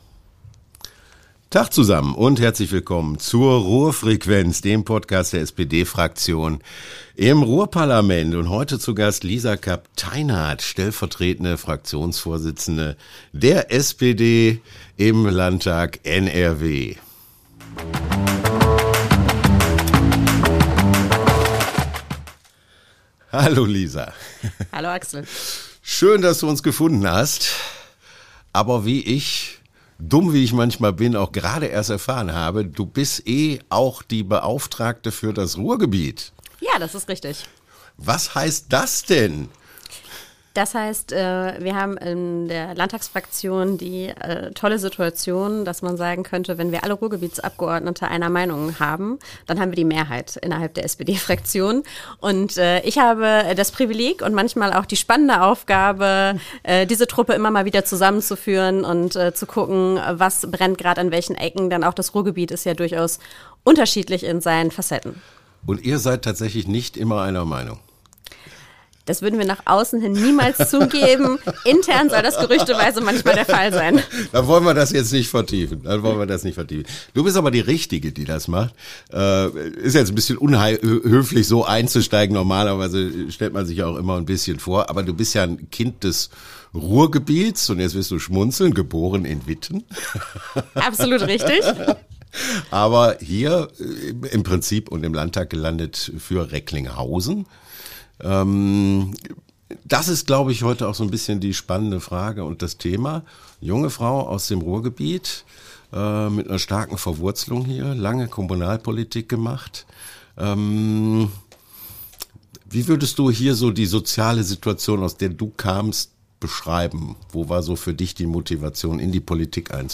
Lisa ist stellvertretende Fraktionsvorsitzende der SPD im Landtag NRW und Beauftragte für das Ruhrgebiet. Mit ihr sprechen wir über die soziale Lage in unserer Region: Warum brauchen wir dringend „Pflegelotsen“, wie können wir Familien bei der täglichen Care-Arbeit besser unterstützen und was bedeutet „Respekt“ eigentlich ganz konkret für die Menschen im Ruhrgebiet?